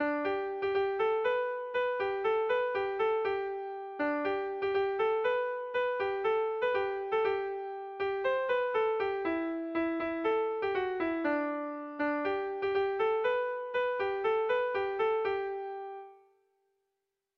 Air de bertsos - Voir fiche   Pour savoir plus sur cette section
Zortziko txikia (hg) / Lau puntuko txikia (ip)
AABA